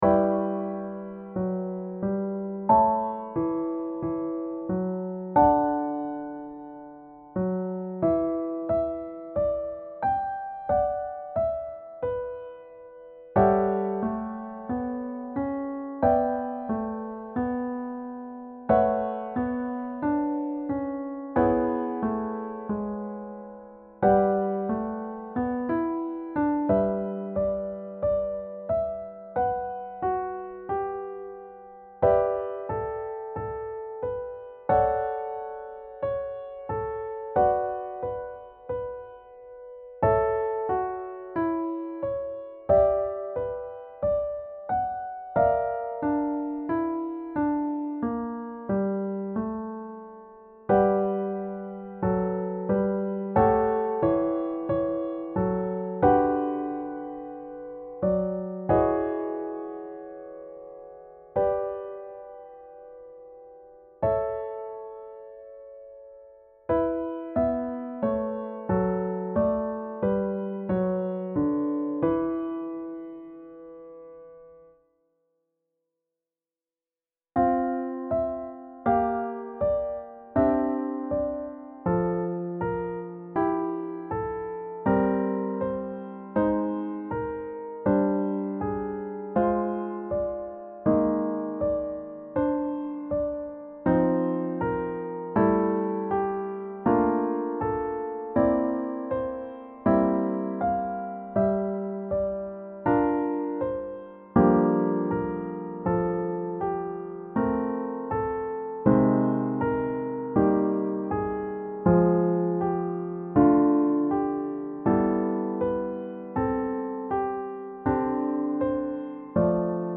for organ
Music Type : Organ